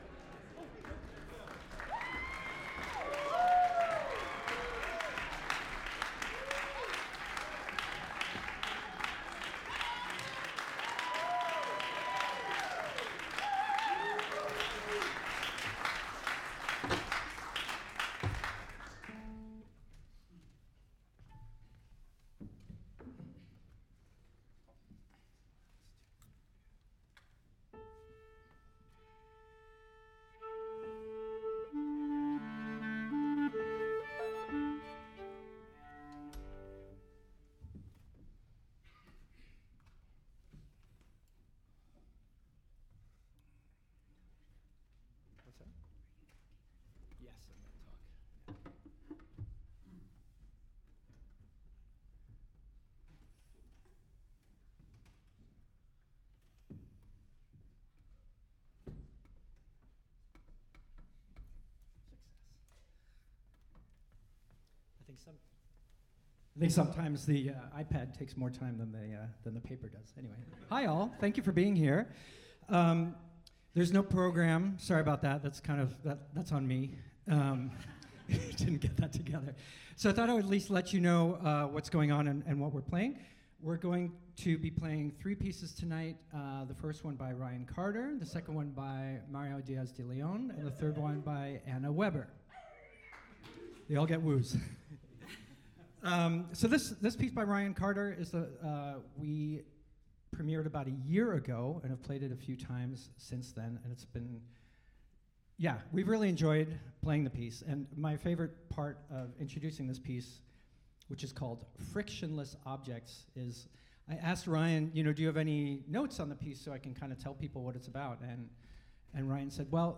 violin
cello
clarinet
piano